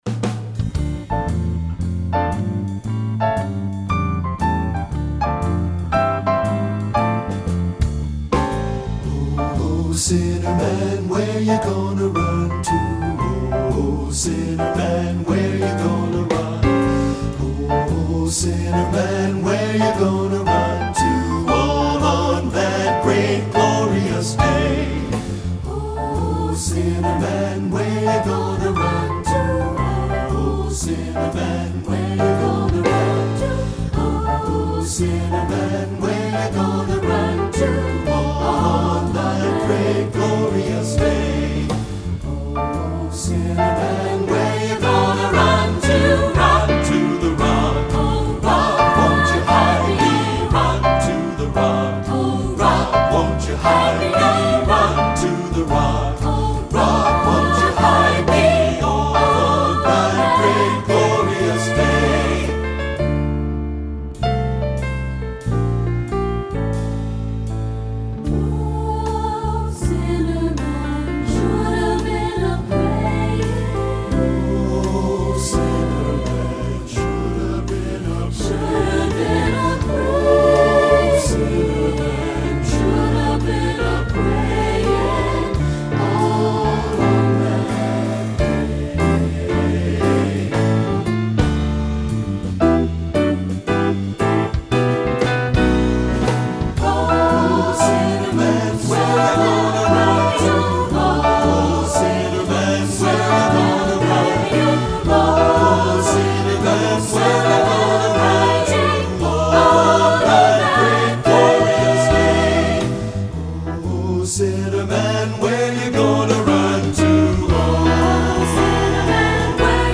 Composer: Spiritual
Voicing: SATB